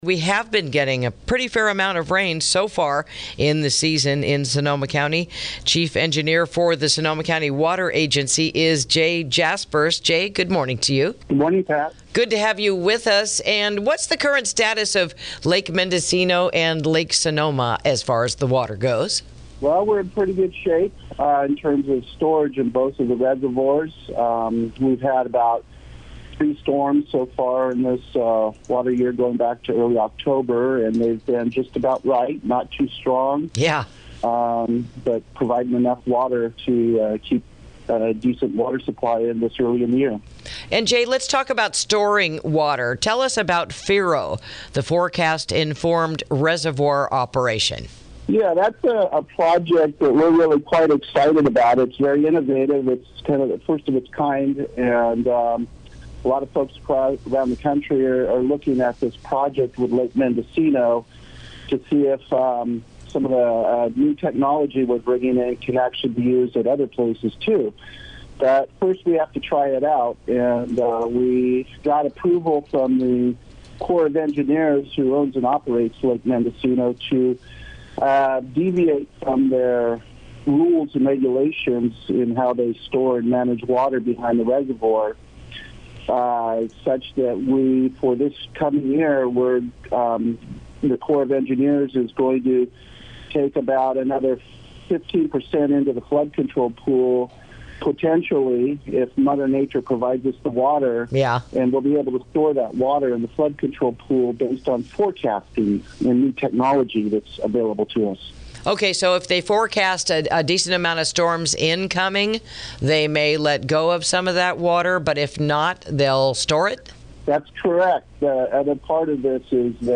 INTERVIEW: New Technology Being Utilized for Water Storage in the North Bay